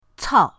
cao4.mp3